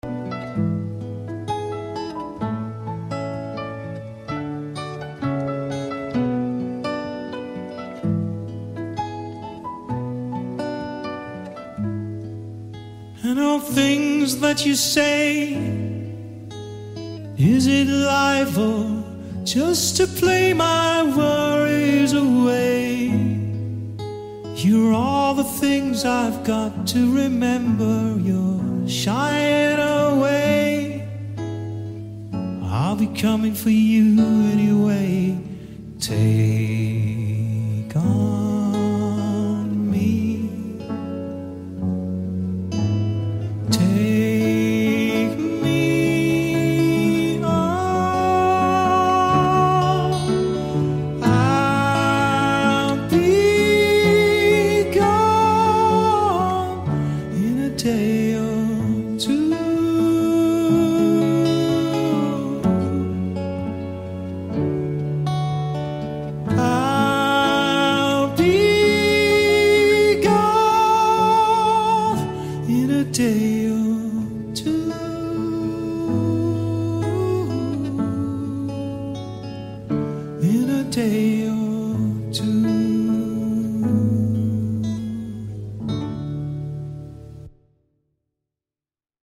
ملودی وکال